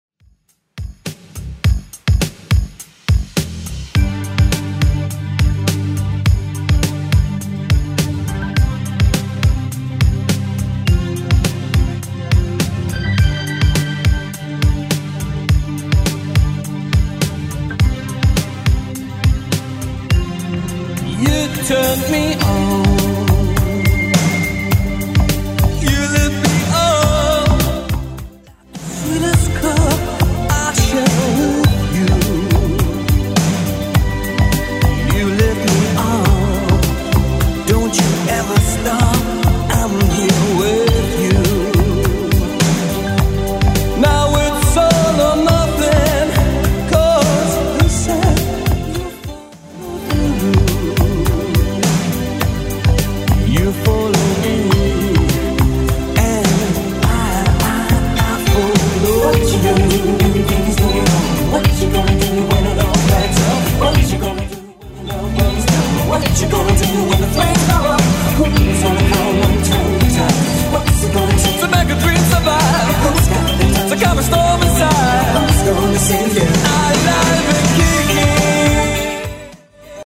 BPM: 104 Time